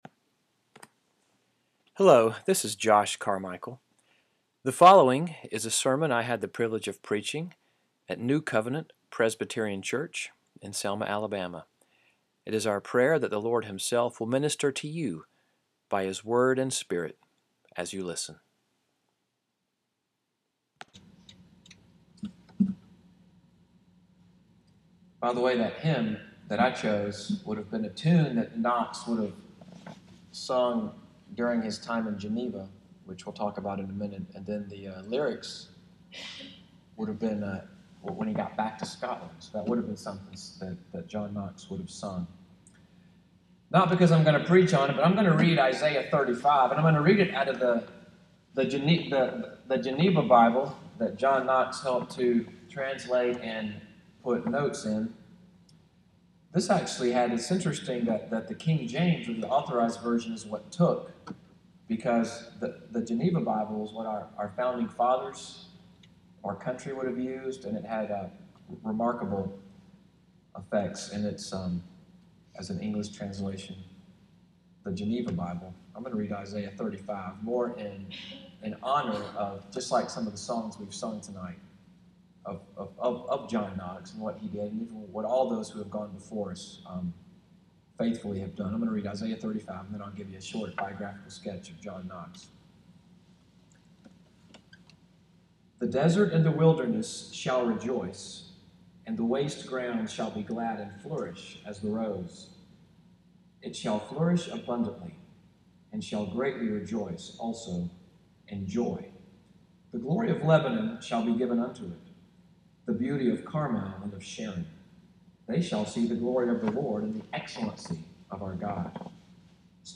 john_knox_talk.mp3